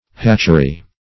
Hatchery \Hatch"er*y\ (-[y^]), n.